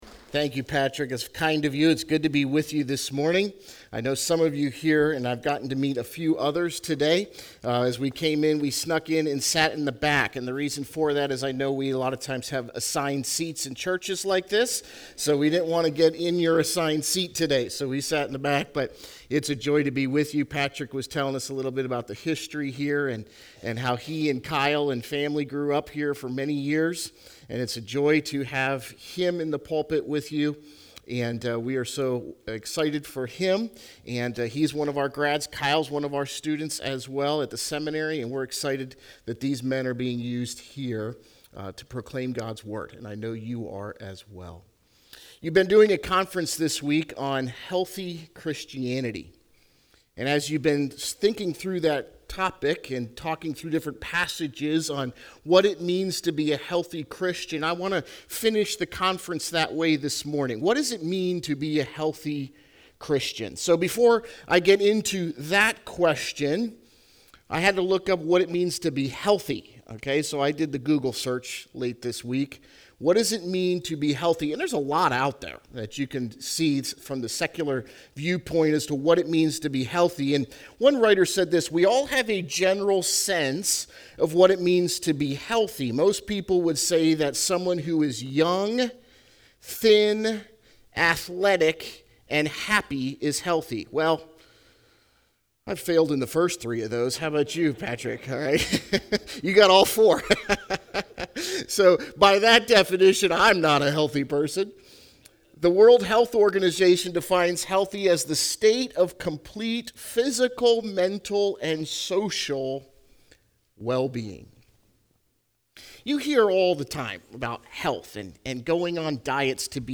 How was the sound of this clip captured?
Fall Bible Conference